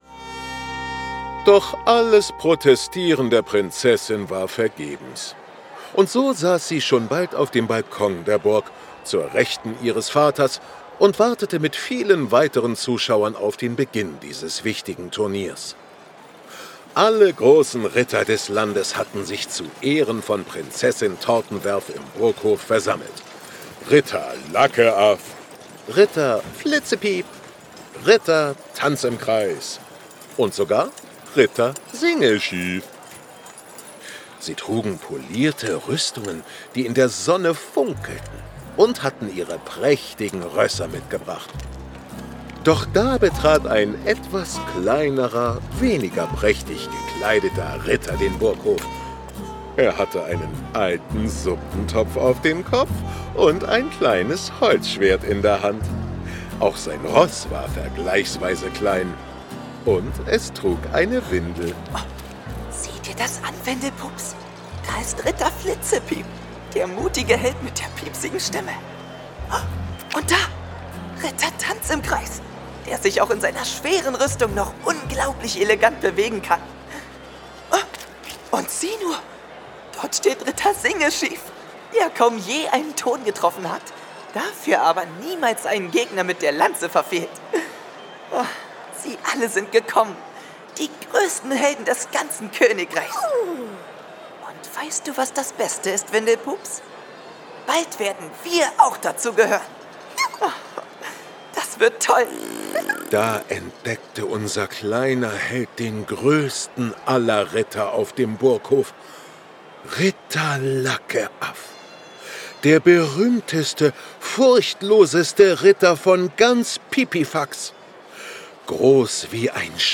Das Musical